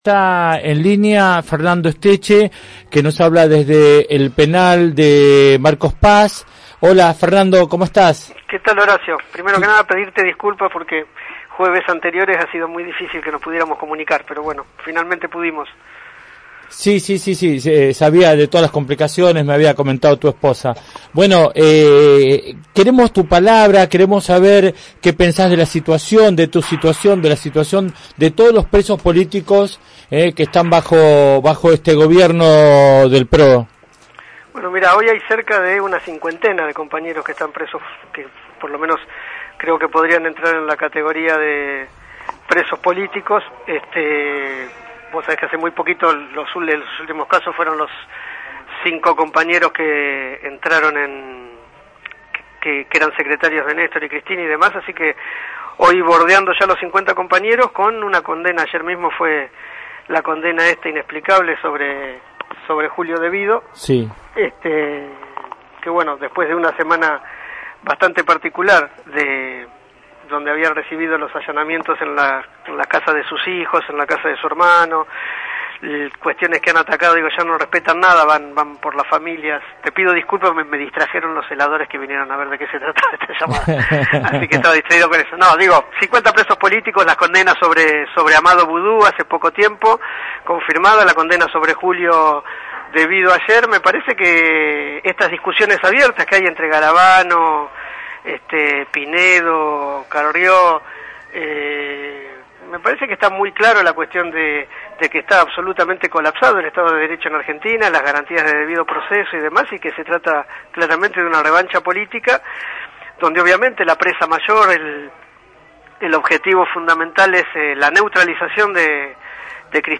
Fernando Esteche desde Marcos Paz